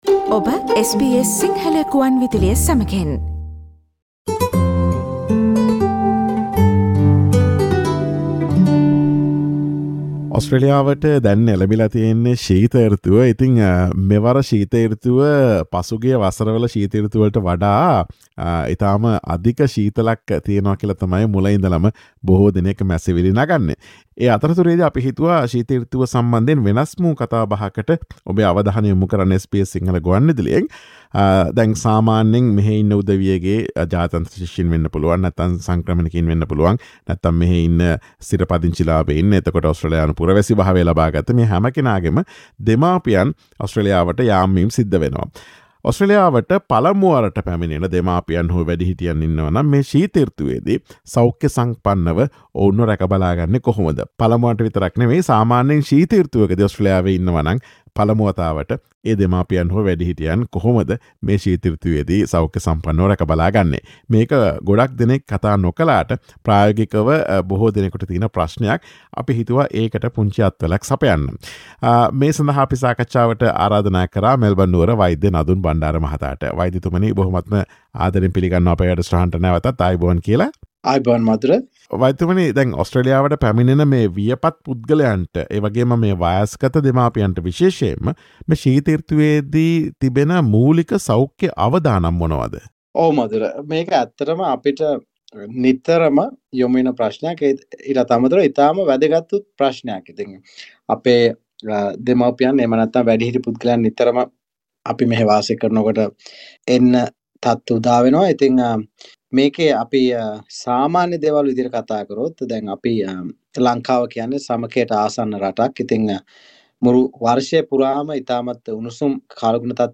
ඔස්ට්‍රේලියාවට පළමු වරට පැමිණෙන දෙමාපියන් හෝ වැඩිහිටියන් ශීත ඍතුවේ දී සෞඛ්‍ය සම්පන්නව රැකබලා ගන්නා අයුරු පිළිබඳ SBS සිංහල ගුවන් විදුලිය සිදුකළ සාකච්ඡාවට සවන් දෙන්න.